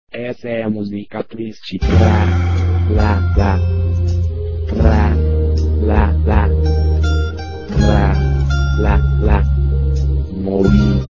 musica triste cantada
musica-triste-cantada.mp3